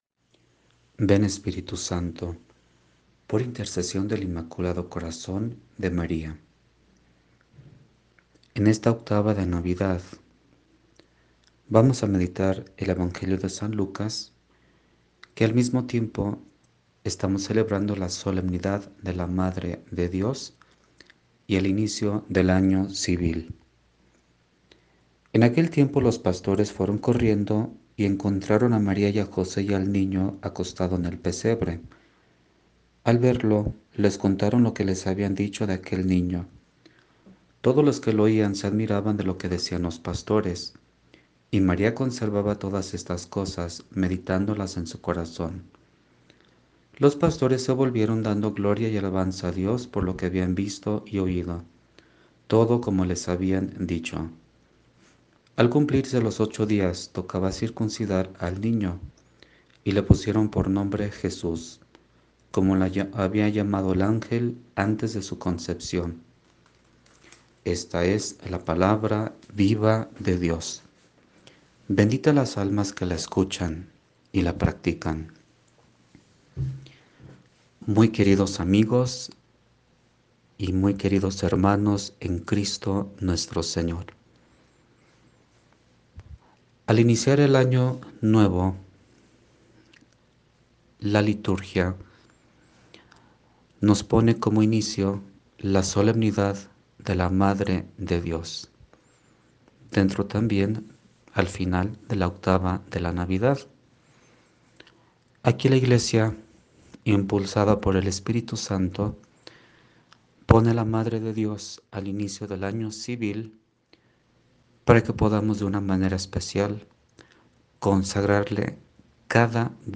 PRÉDICAS EN AUDIO. Solemnidad de la Madre de Dios - Como Vara de Almendro